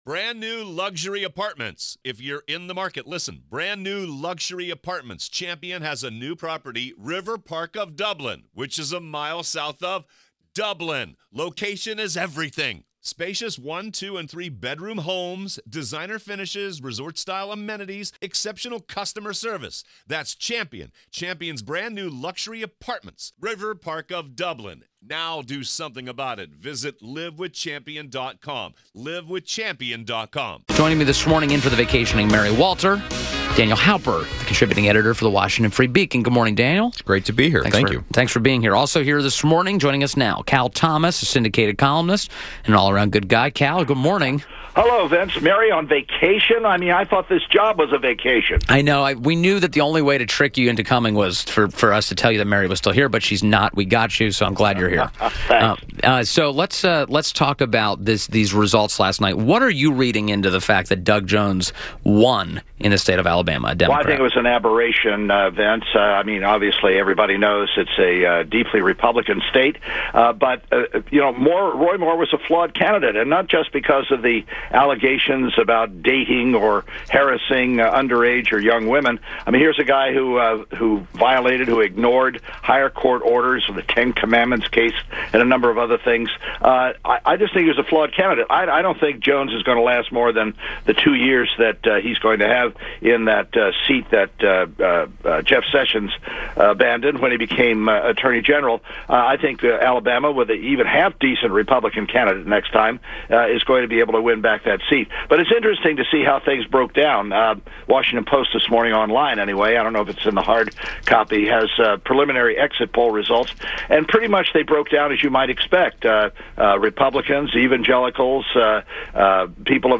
INTERVIEW - CAL THOMAS - Syndicated columnist